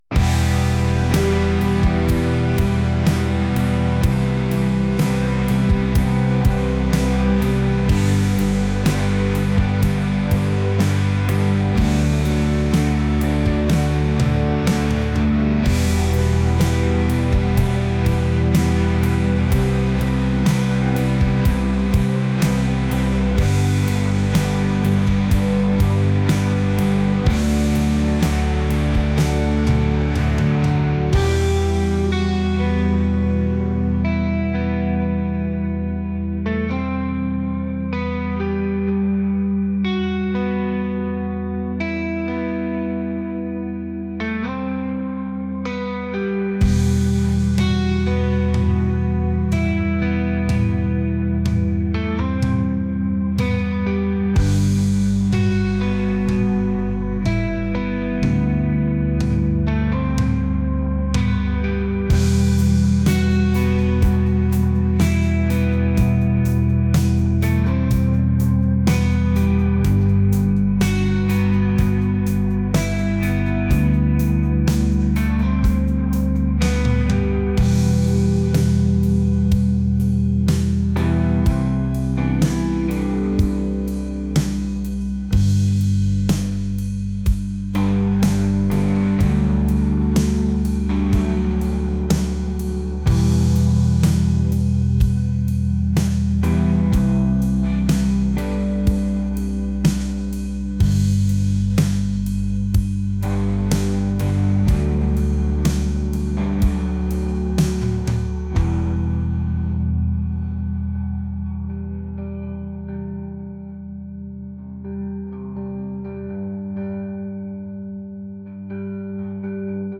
indie | rock | ambient